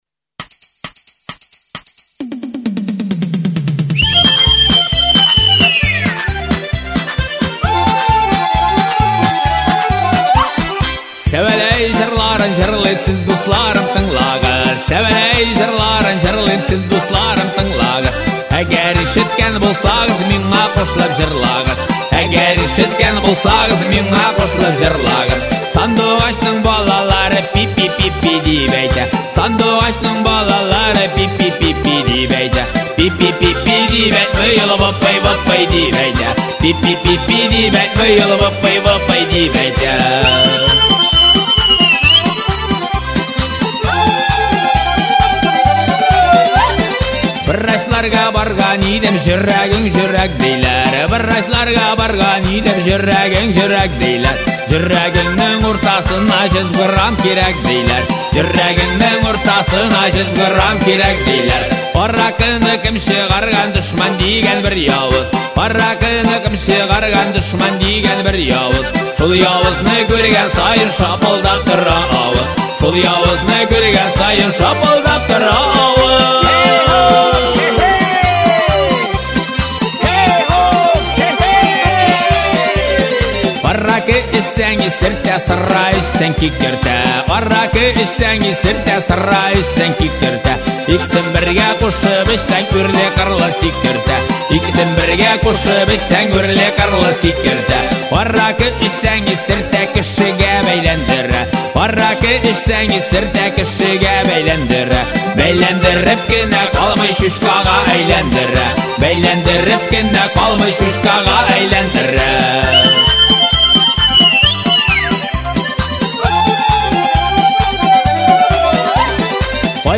татарская музыка